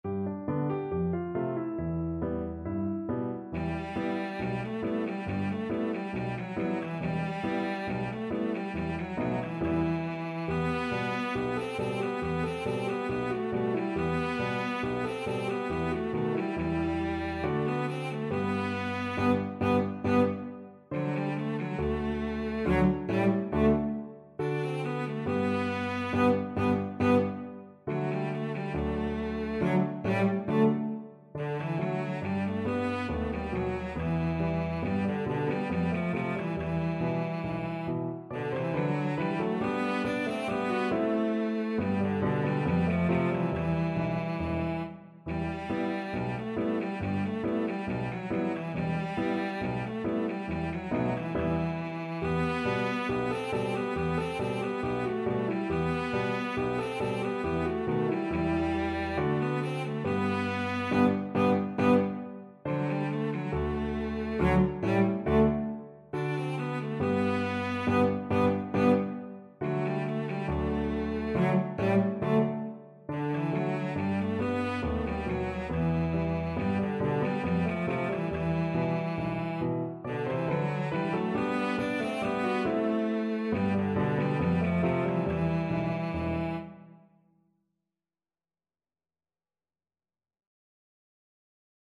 Cello
Traditional Music of unknown author.
2/4 (View more 2/4 Music)
E minor (Sounding Pitch) (View more E minor Music for Cello )
Slow =69
patch_tanz_VLC.mp3